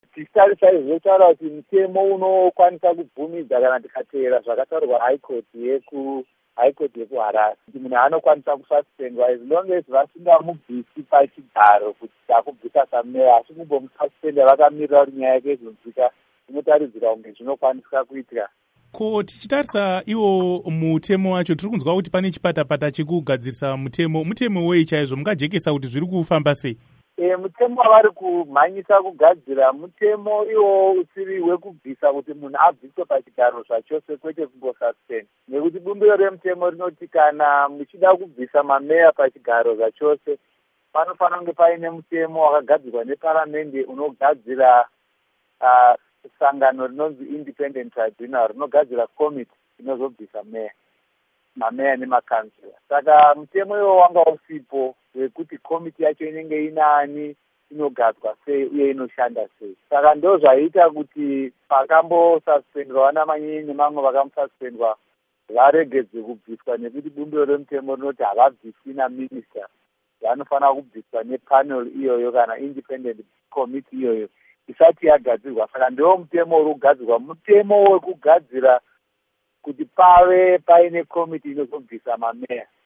Hurukuro naMuzvinafundo Lovemore Madhuku